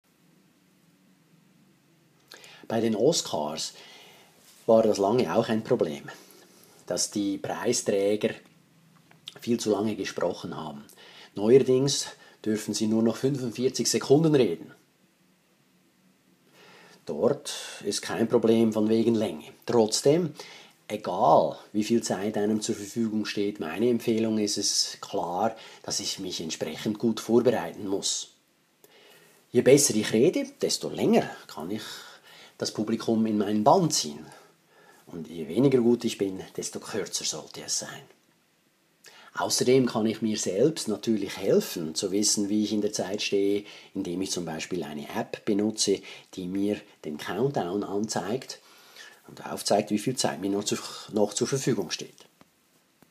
Dankesrede 3